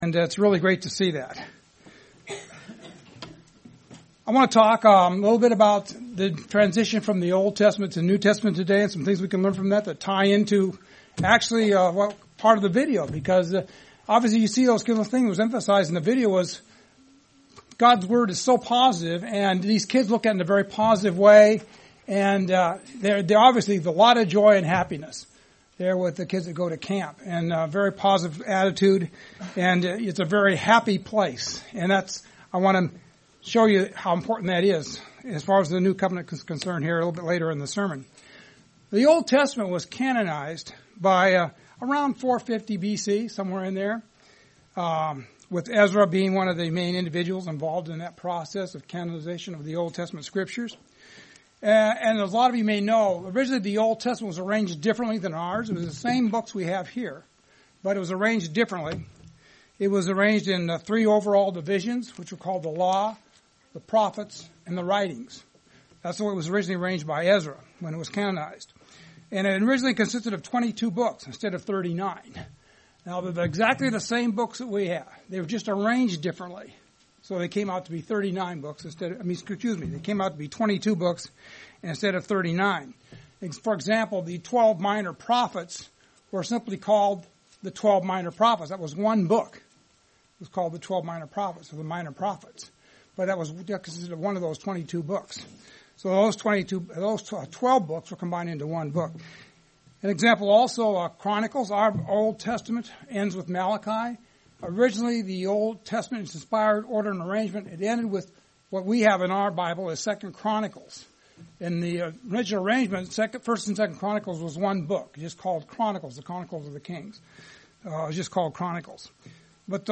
Some lesson we can learn from the transition between the Old Testament to the New Testament and the tools needed to build a spiritual temple. In part 1 of a two part sermon series, we will look at some of the tools needed as listed in Jesus Christ's Sermon on the Mount.